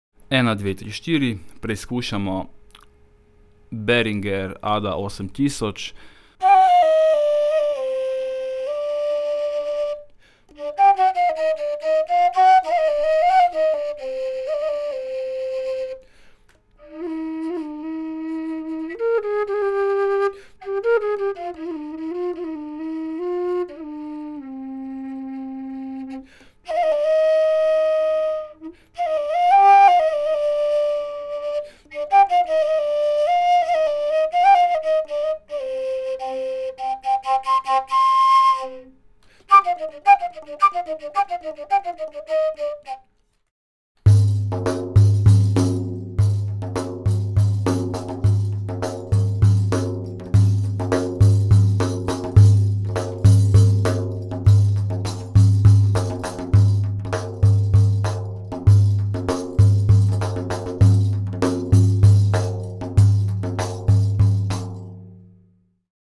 test 2. je Behringer
Mikrofon je shure SM81, posnet pa je govor, makedonski duduk (pihalo) in
obrocni boben!
behringer_ada-8000_test2.mp3